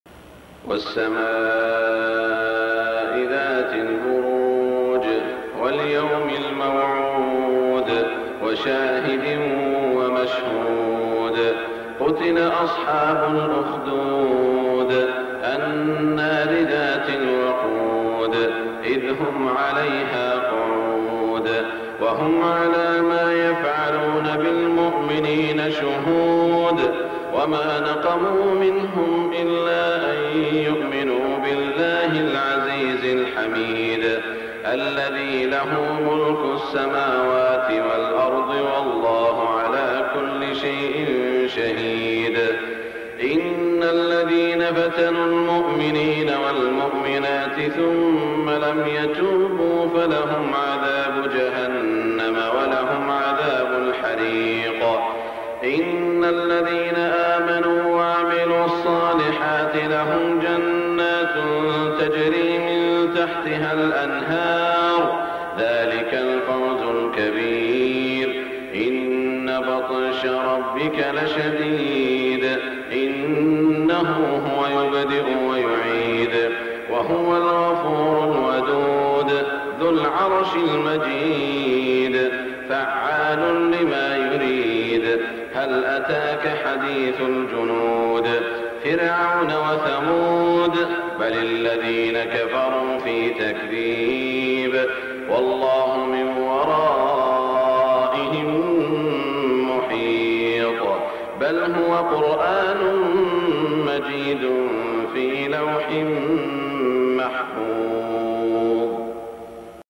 صلاة الفجر 1423هـ سورة البروج > 1423 🕋 > الفروض - تلاوات الحرمين